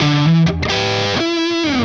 AM_HeroGuitar_130-E02.wav